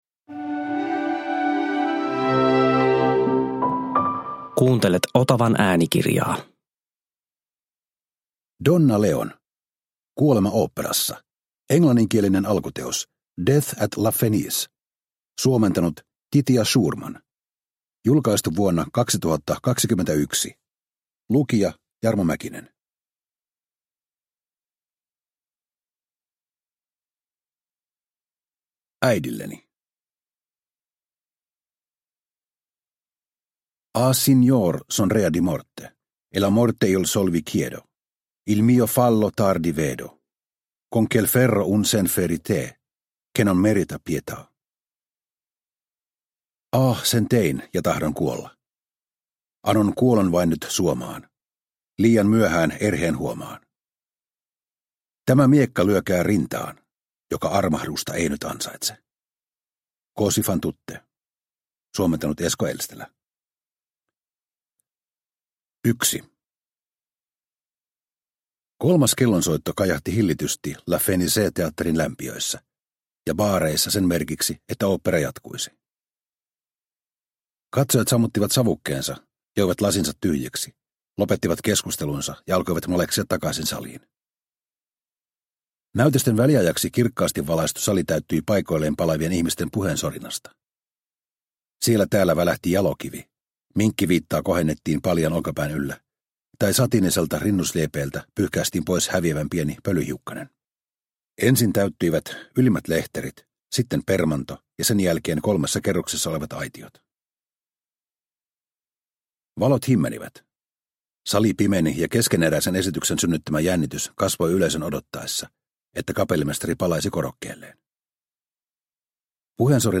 Kuolema oopperassa – Ljudbok – Laddas ner